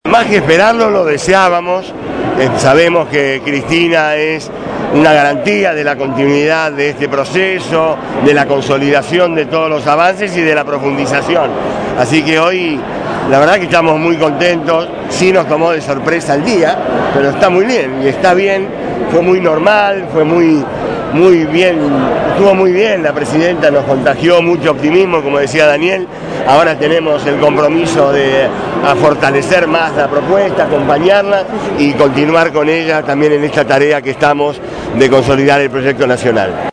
registró para los micrófonos de Radio Gráfica FM 89.3 los testimonios de la jornada.